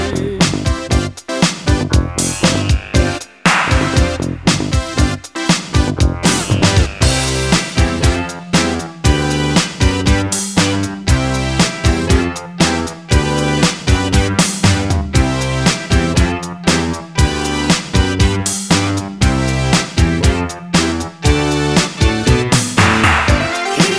Duet Version Pop (1980s) 3:47 Buy £1.50